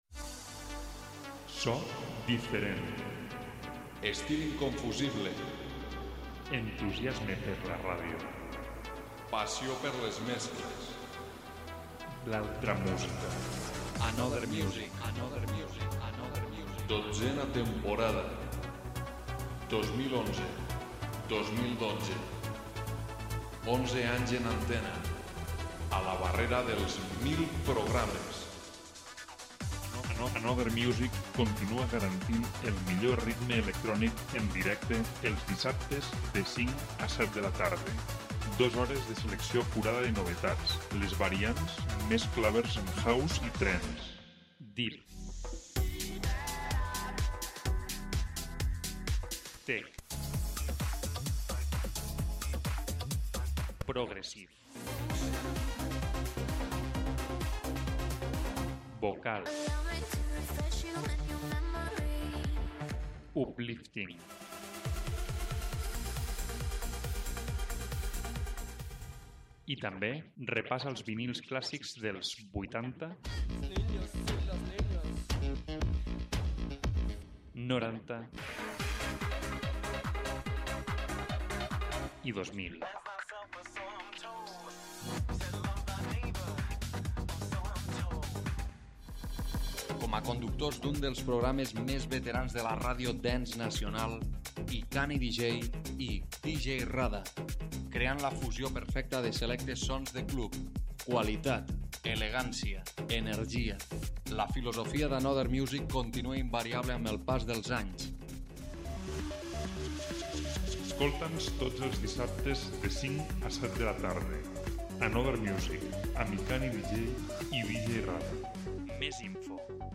música electrònica exclusiva